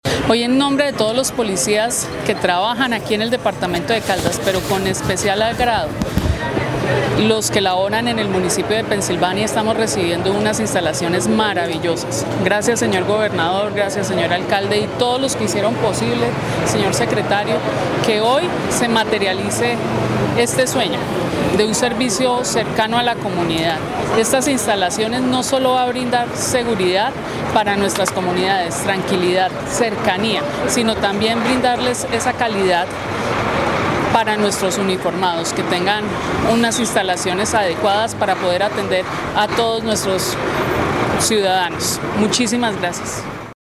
Coronel Liliana Andrea Jiménez Falla, comandante Departamento de Policía Caldas